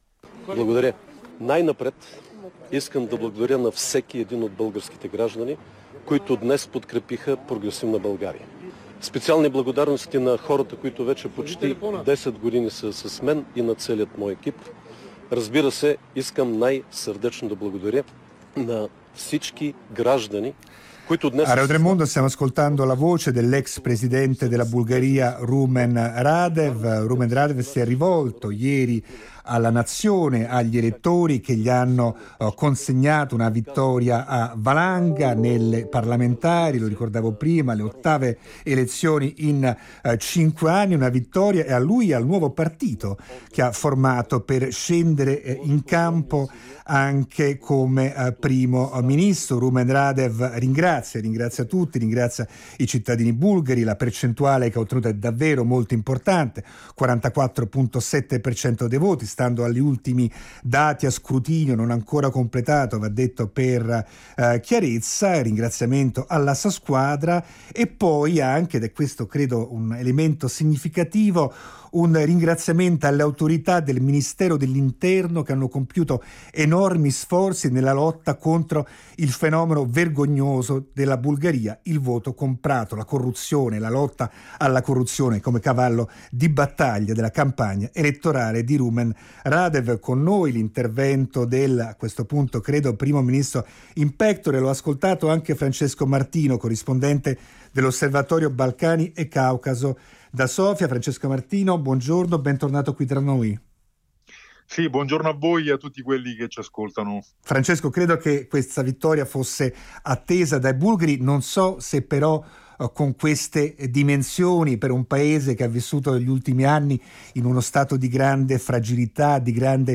L’ex presidente bulgaro Rumen Radev ha vinto le elezioni parlamentari con il 44,58% dei voti, all’ottava tornata elettorale anticipata nel paese degli ultimi cinque anni. L’analisi in diretta da Sofia